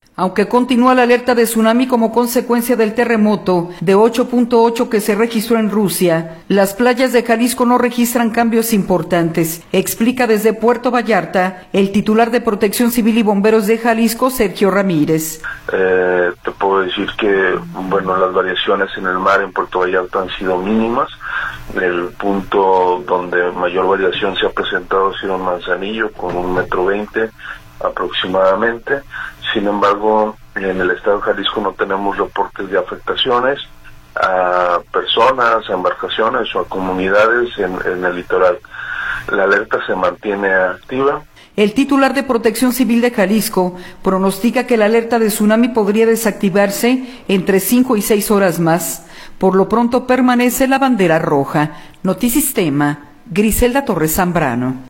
audio Aunque continúa la alerta de tsunami como consecuencia del terremoto de 8.8 que se registró en Rusia, las playas de Jalisco no registran cambios importantes, explica desde Puerto Vallarta el titular de Protección Civil y Bomberos de Jalisco, Sergio Ramírez.